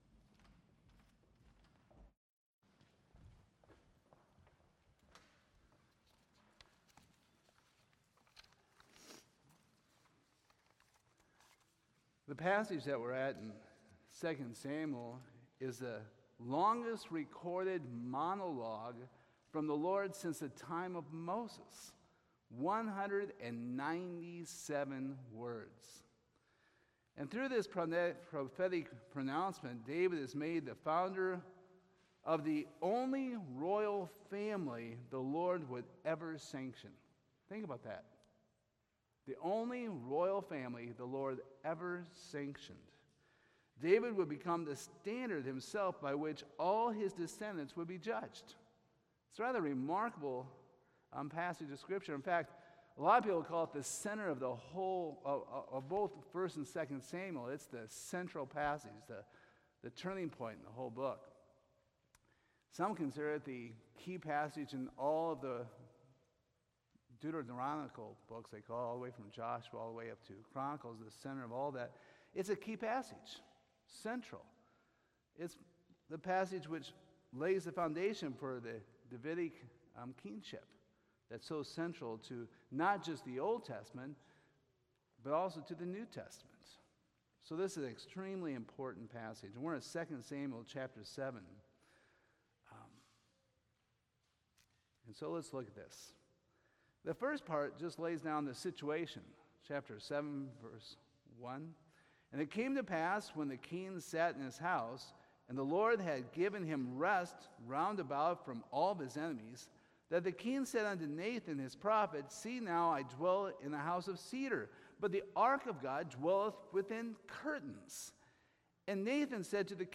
2 Samuel 7:1-17 Service Type: Sunday Evening 2 Samuel 7 is one of the most central passages to the Old Testament.